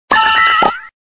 Fichier:Cri 0421 DP.ogg